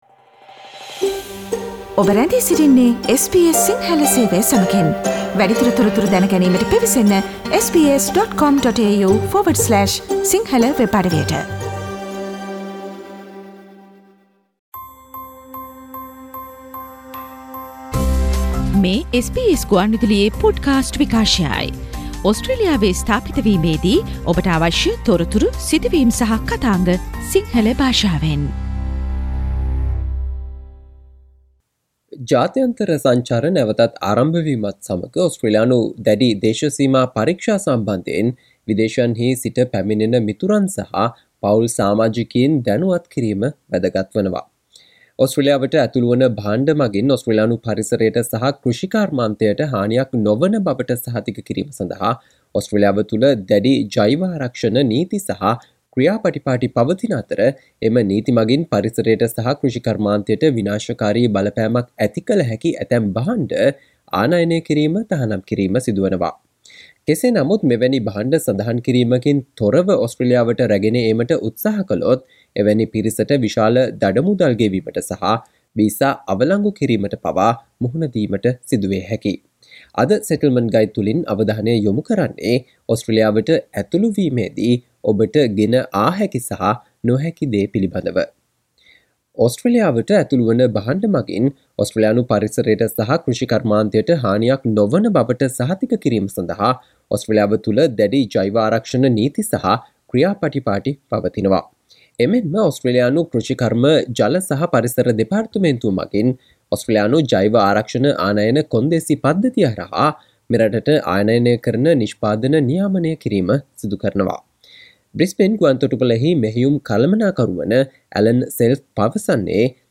අද මෙම Settlement Guide ගුවන්විදුලි විශේෂාංගයෙන් අපි ඔබ වෙත ගෙන ආ තොරතුරු, කියවා දැන ගැනීමට හැකි වන පරිදි වෙබ් ලිපියක් ආකාරයටත් අපගේ වෙබ් අඩවියේ පලකොට තිබෙනවා.